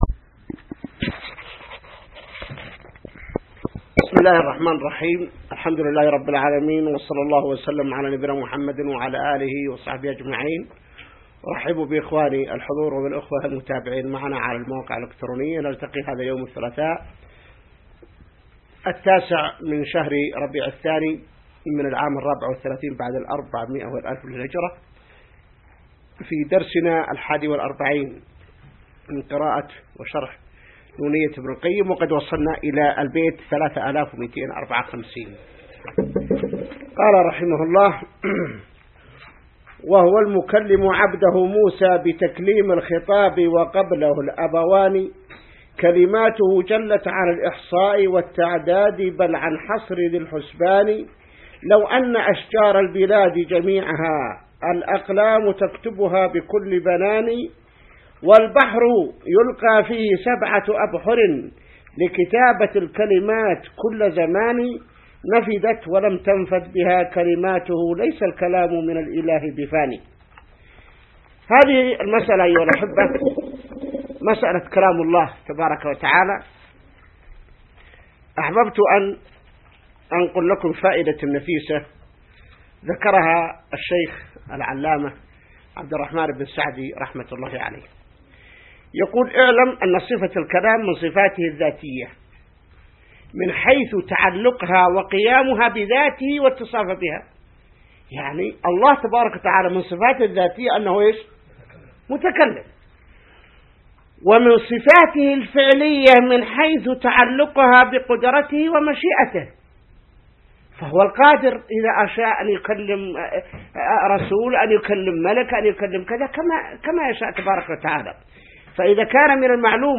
الدرس 41 من شرح نونية ابن القيم | موقع المسلم